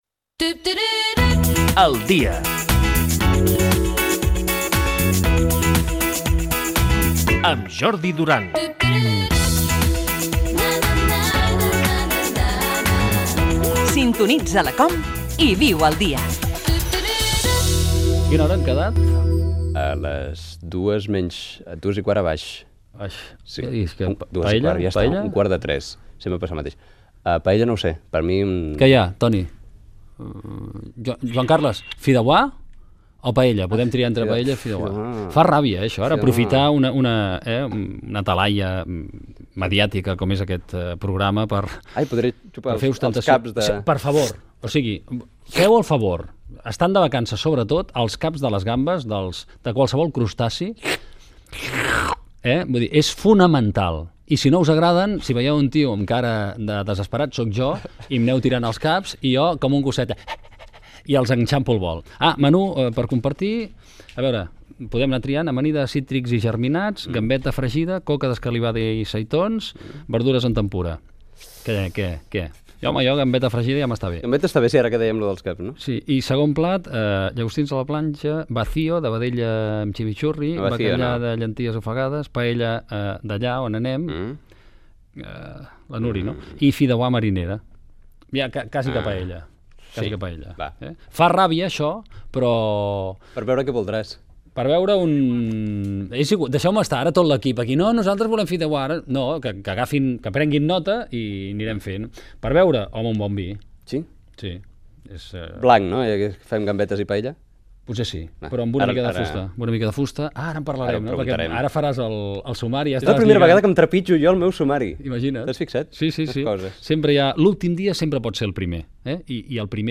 Info-entreteniment
Fragment extret de l'arxiu sonor de COM Ràdio